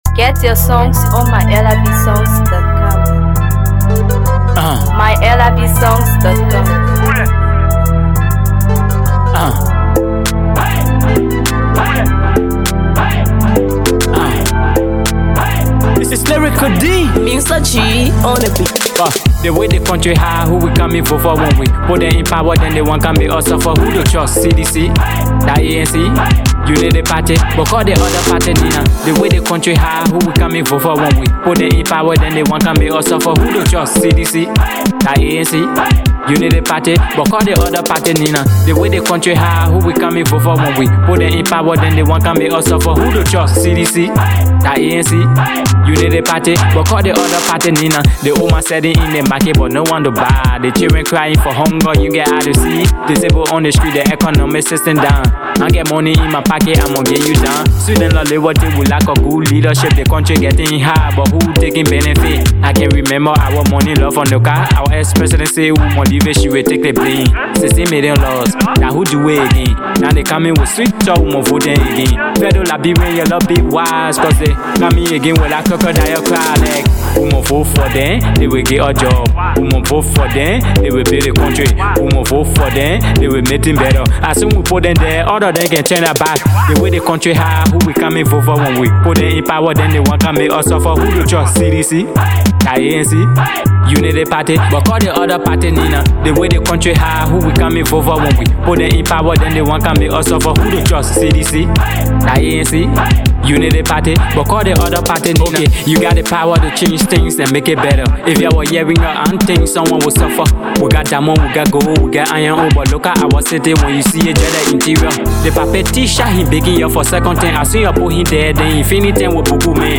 Afro PopMusic
Political Banger